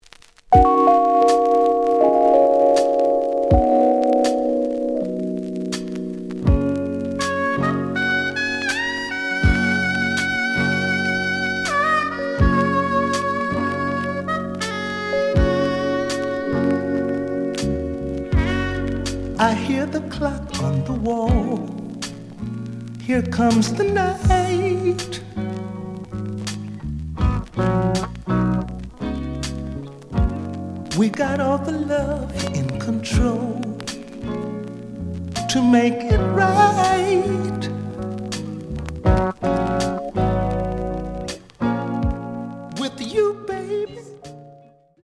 The audio sample is recorded from the actual item.
●Genre: Soul, 80's / 90's Soul
Slight noise on both sides.